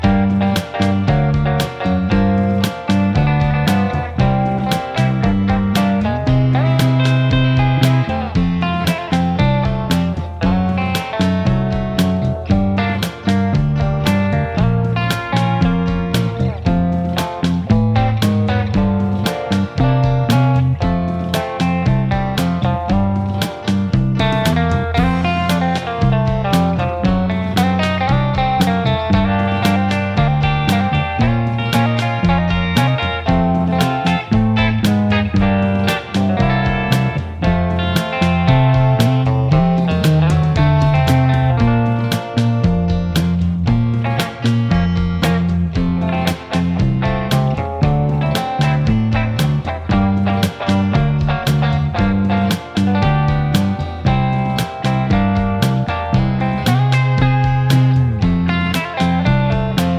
Minus All Guitars Pop (1960s) 3:20 Buy £1.50